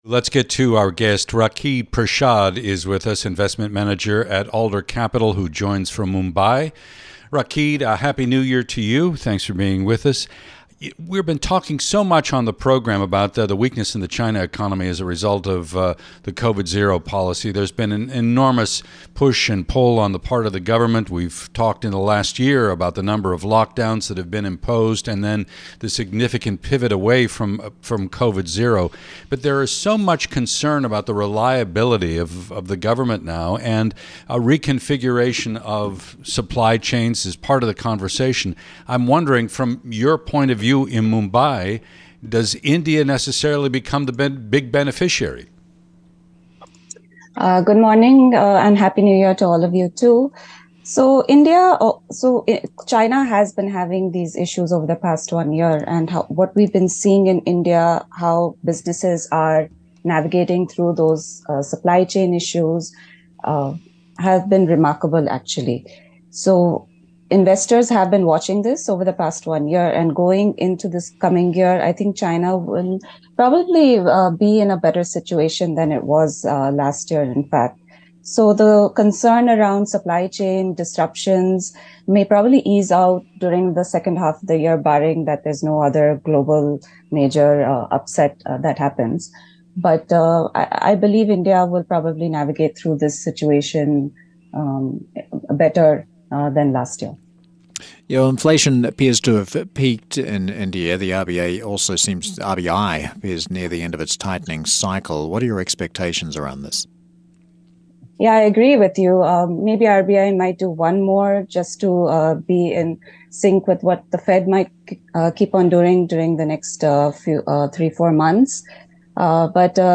(Radio)